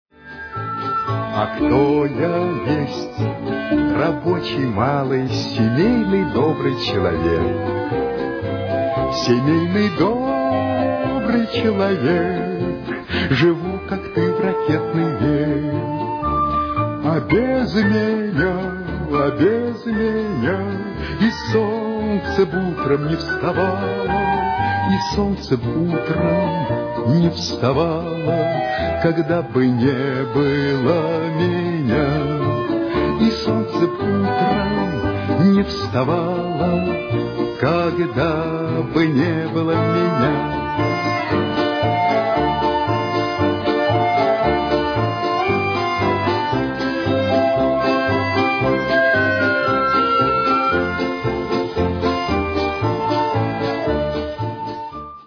имея в виду их задушевную манеру исполнения.